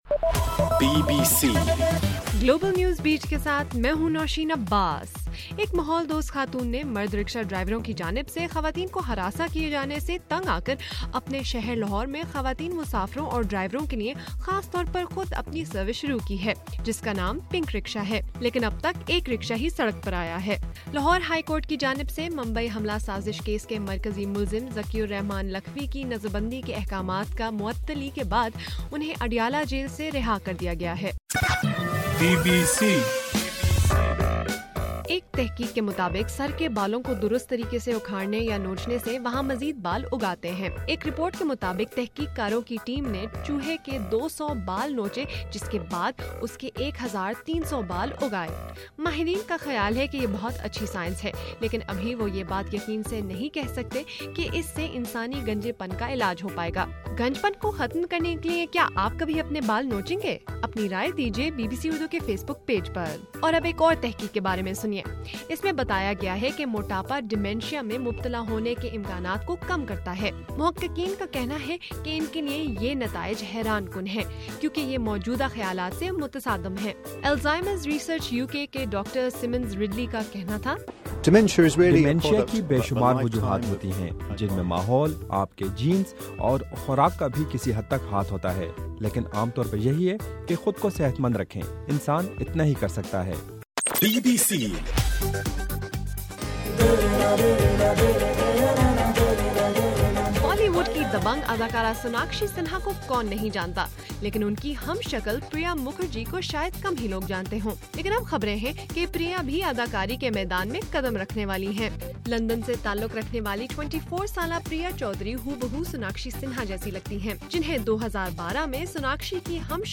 اپریل 10: رات 8 بجے کا گلوبل نیوز بیٹ بُلیٹن